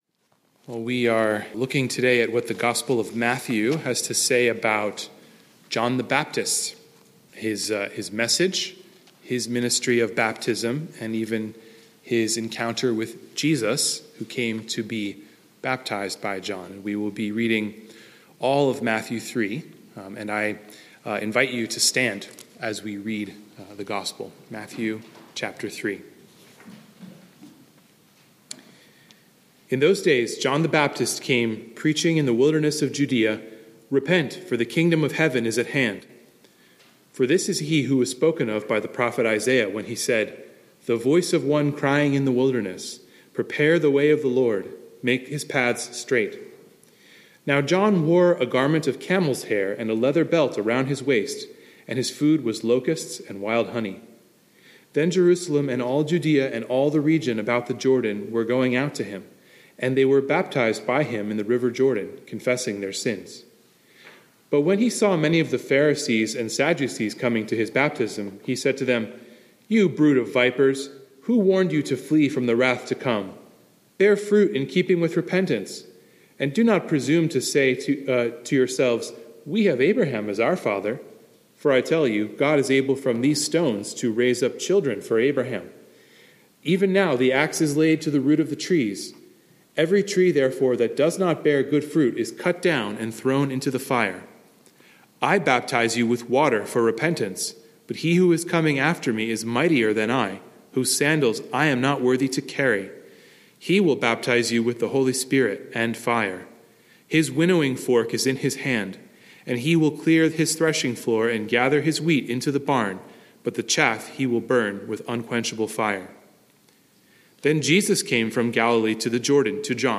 Sermon Scripture: Matthew 3